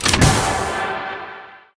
healthpickup.ogg